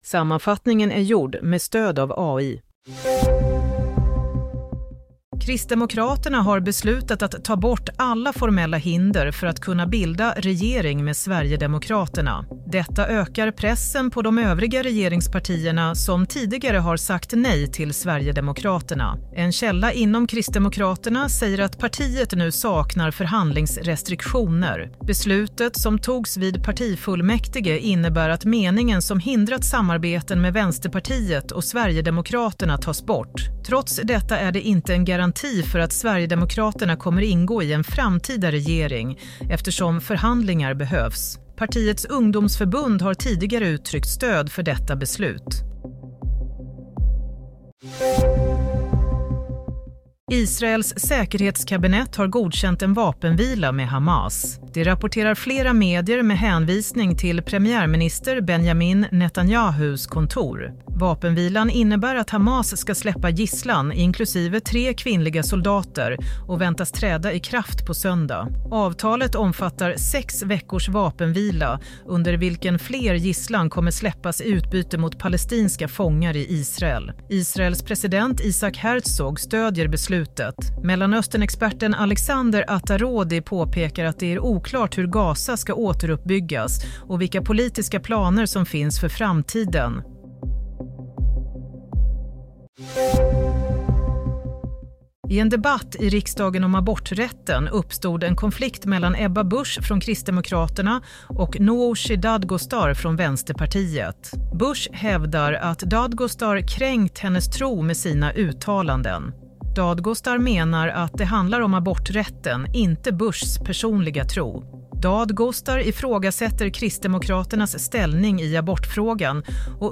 Nyhetssammanfattning - 17 januari 16:00
Sammanfattningen av följande nyheter är gjord med stöd av AI.